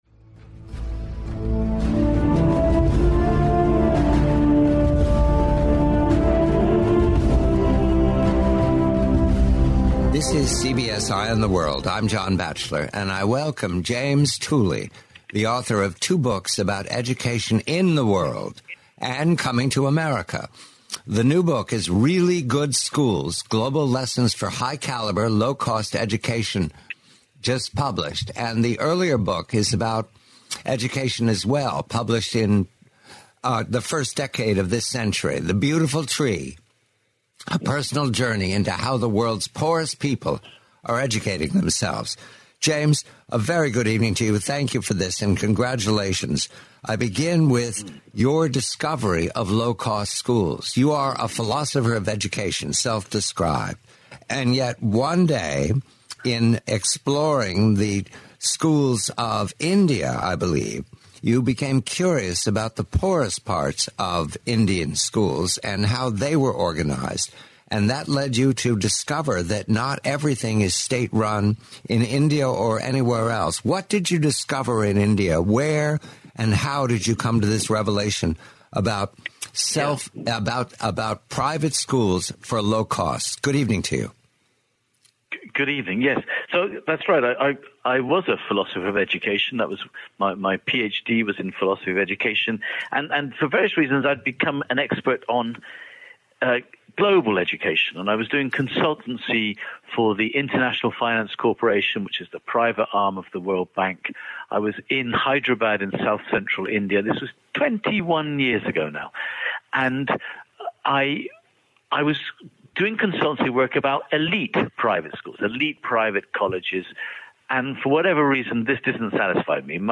the complete, forty-minute interview.